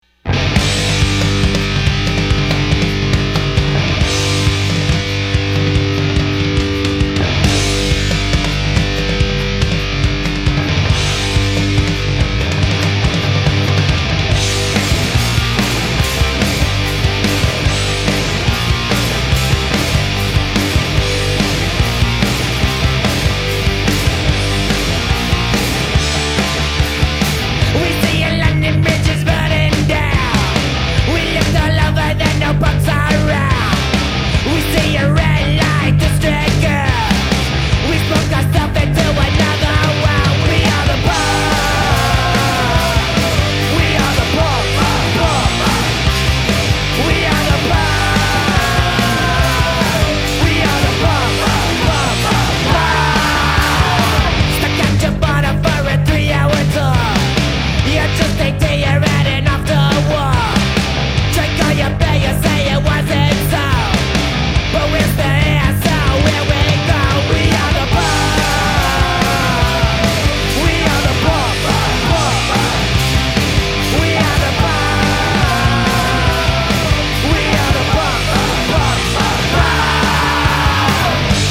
Vocals
Drums
Guitar
Bass